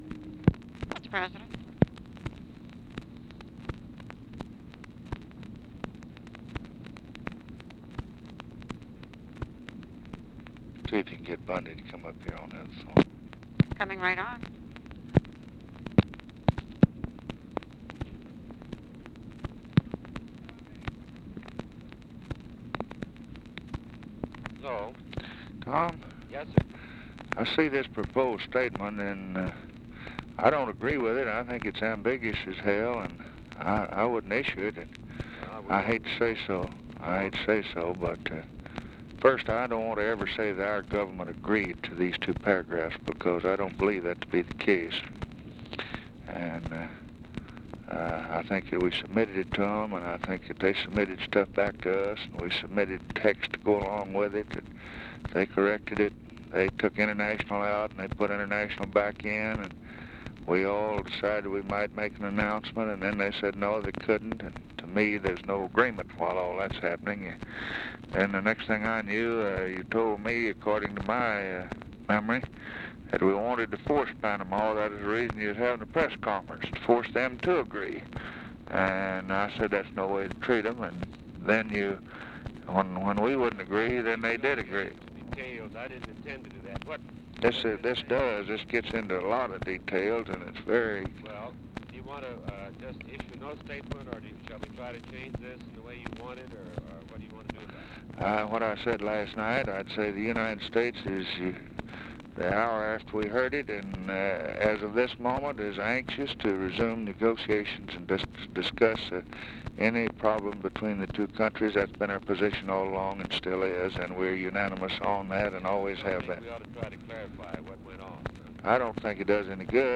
Conversation with THOMAS MANN, MCGEORGE BUNDY and OFFICE CONVERSATION, March 19, 1964